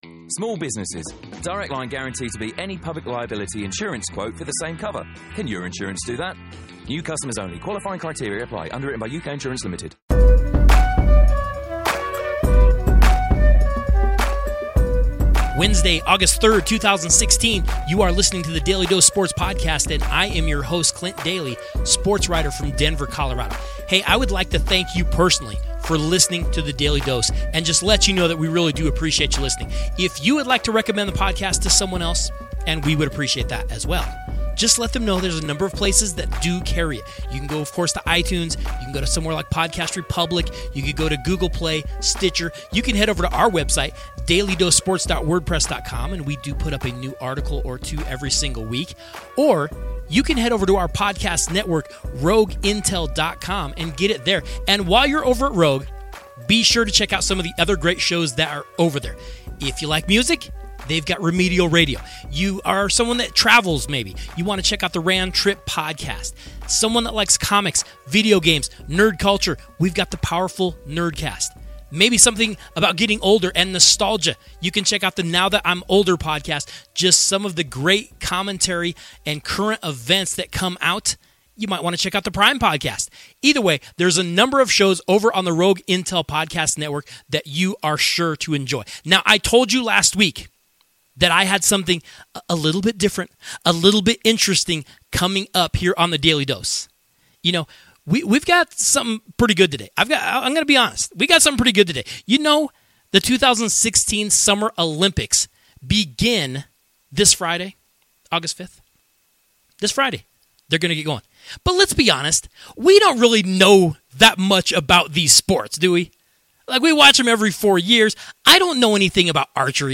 This is a Daly Dose interview that you do NOT want to miss.